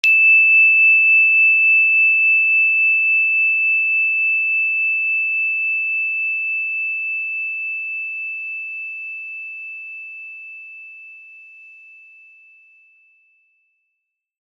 energychime_wood-E6-pp.wav